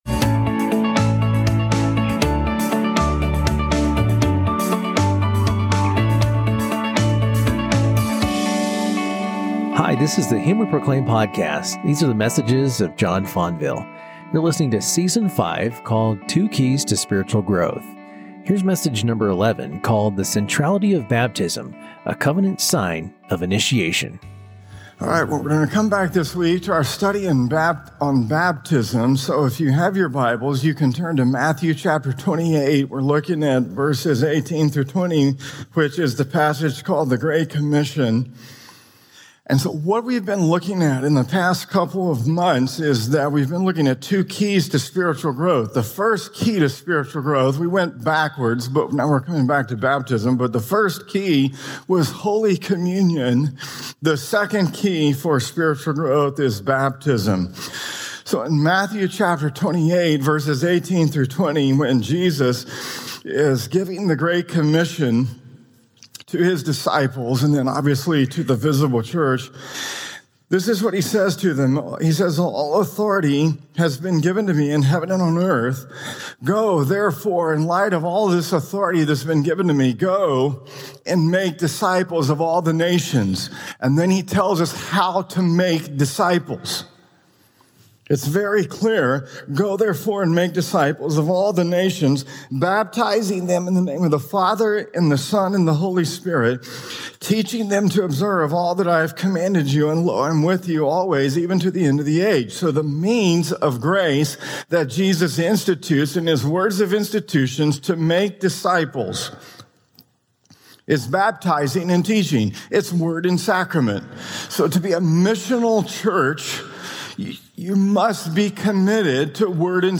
Gospel Blessings!!We are continuing our theme of "best of" episodes.